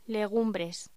Locución: Legumbres